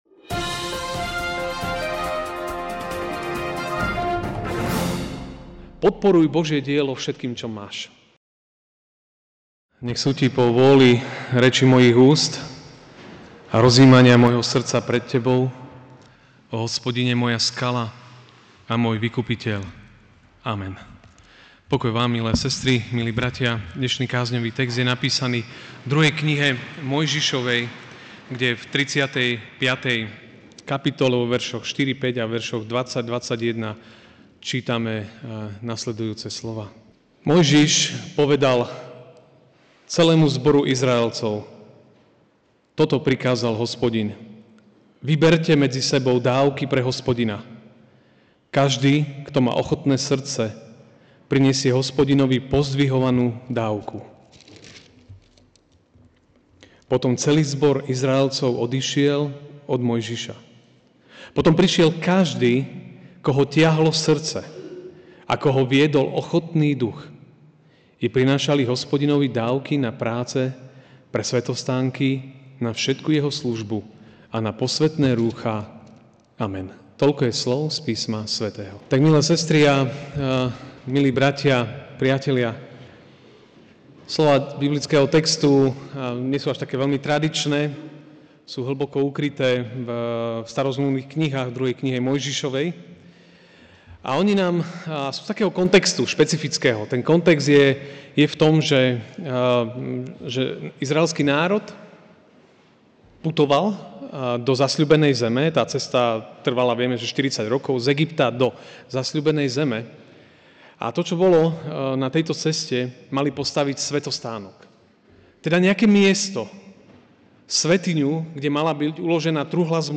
Ranná kázeň: Ochotný postoj!